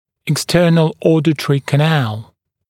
[ɪk’stɜːnl ‘ɔːdətrɪ kə’næl] [ek-][ик’стё:нл ‘о:дэтри кэ’нэл] [эк-]наружний слуховой проход